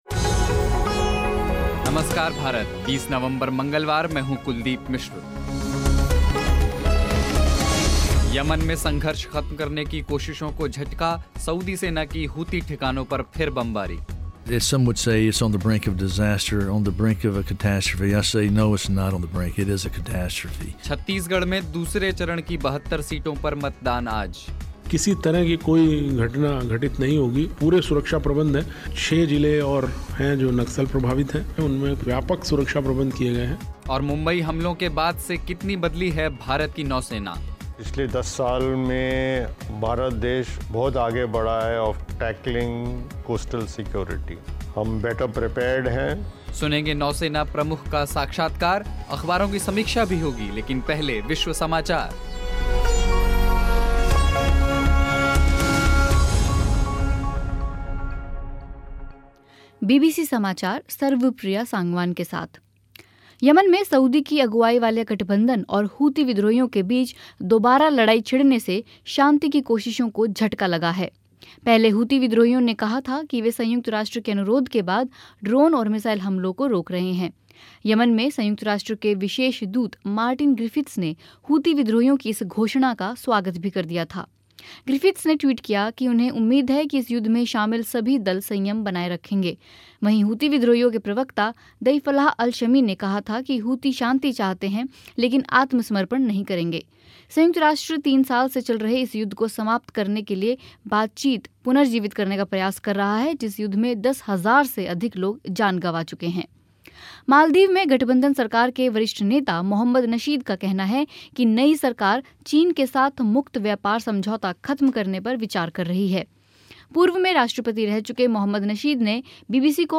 मुंबई हमलों के बाद से कितनी बदली है भारत की नौसेना, नौसेना प्रमुख का साक्षात्कार अख़बारों की समीक्षा भी